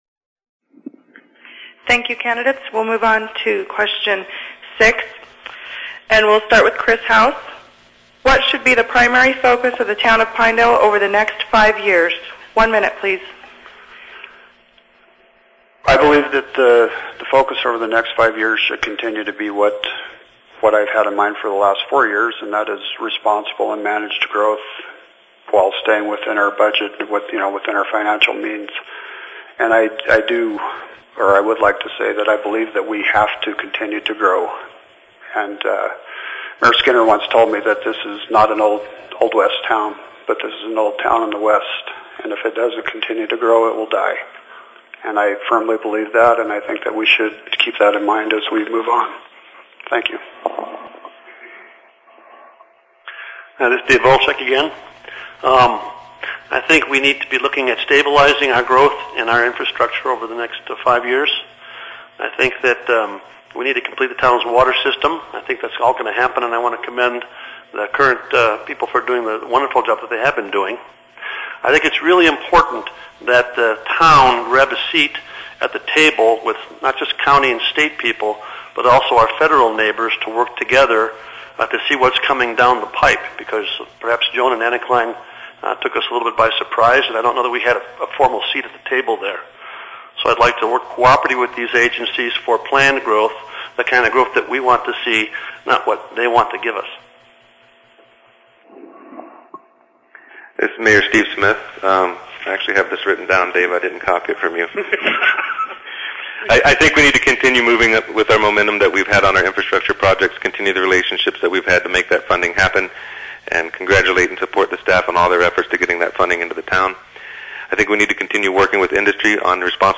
Below are the audio files from the Wednesday, April 28, 2010 Candidate Forum in the Lovatt Room of the Sublette County Library in Pinedale. Candidates for Town of Pinedale Mayor (M) and two open Town Council (TC) positions participated.